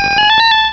pokeemerald / sound / direct_sound_samples / cries / porygon2.aif
-Replaced the Gen. 1 to 3 cries with BW2 rips.